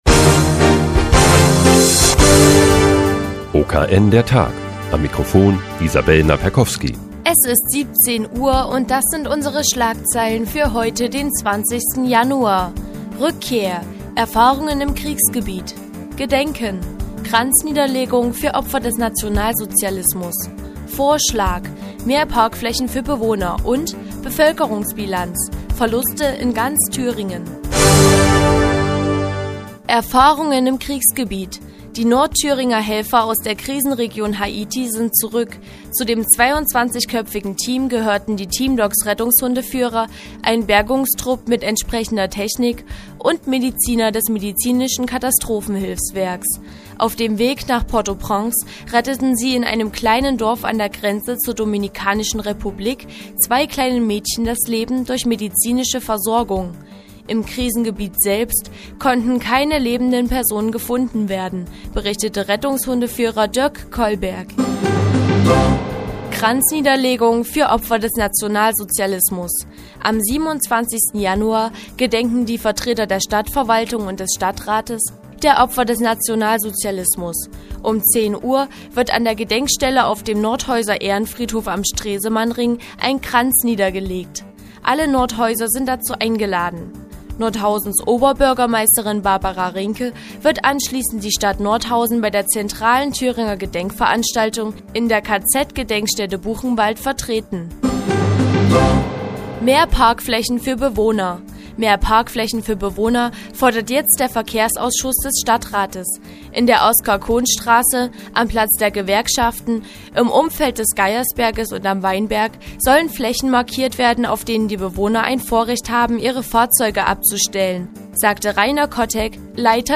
Die tägliche Nachrichtensendung des OKN ist nun auch in der nnz zu hören. Heute geht es um eine Kranzniederlegung zum Gedenken an die Opfer des Nationalsozialismus und neue Parkflächen für Bewohner.